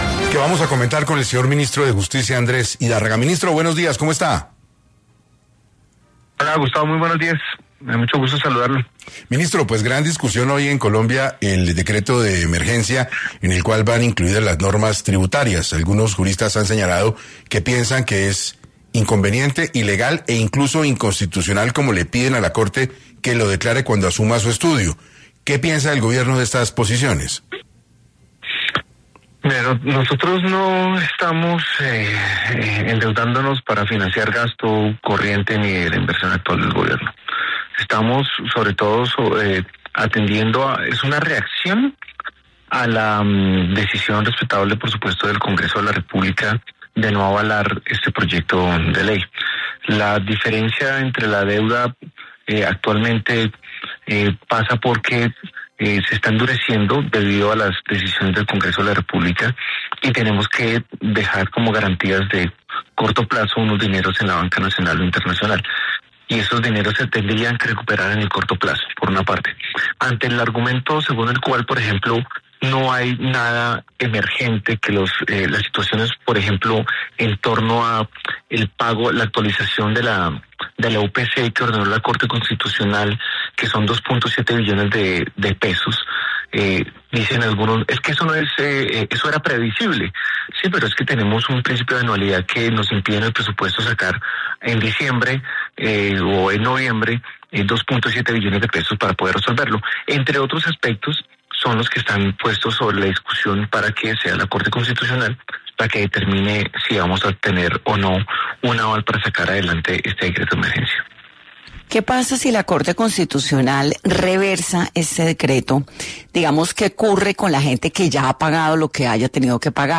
En 6AM de Caracol Radio estuvo , Andrés Idárraga, Ministro de justicia enfatizó cuáles son los argumentos del decreto tras no aprobar la ley de financiamiento